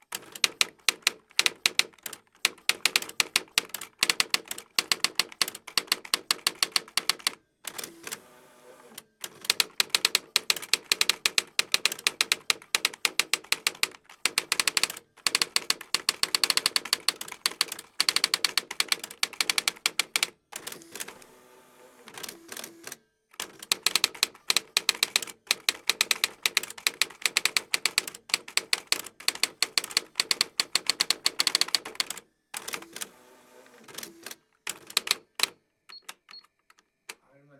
Escribir en una máquina de escribir electrónica
máquina de escribir
Sonidos: Oficina